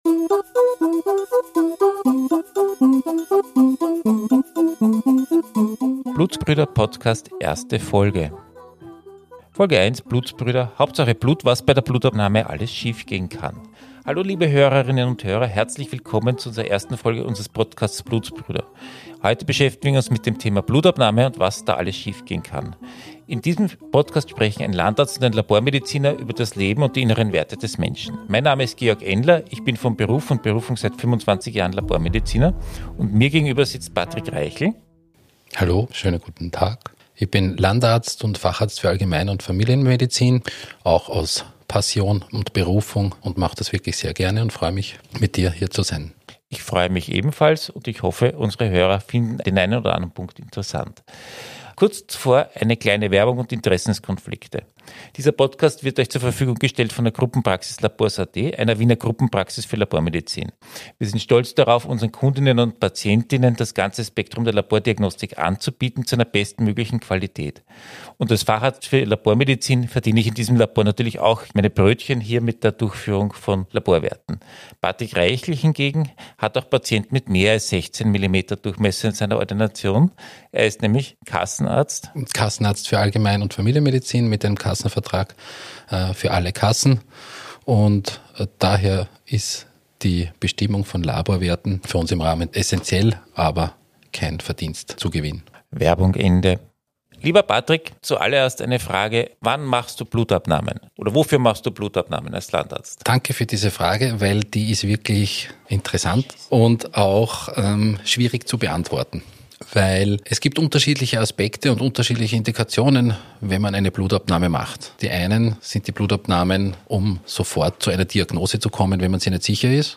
Blutsbrüder Folge 1 - Hauptsache Blut fließt - was bei der Blutabnahme alles schief gehen kann ~ Blutsbrüder - Ein Landarzt und ein Labormediziner sprechen über die inneren Werte Podcast